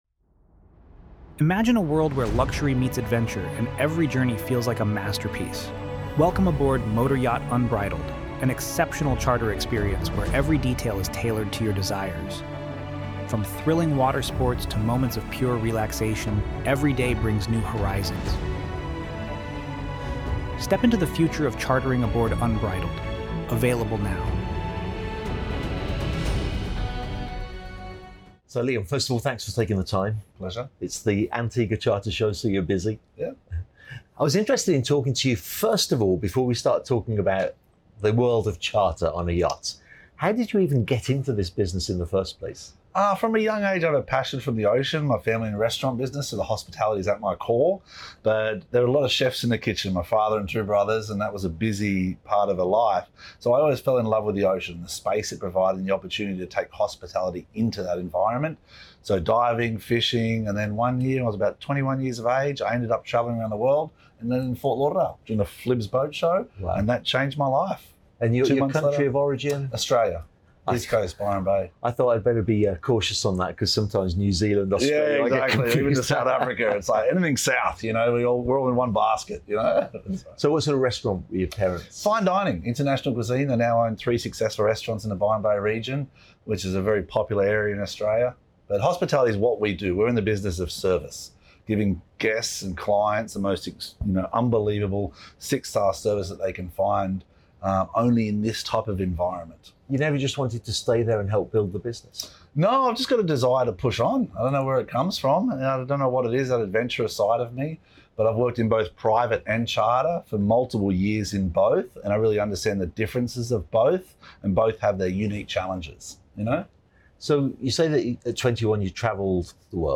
recorded at the Antigua Charter Show